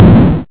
Tree Cut.ogg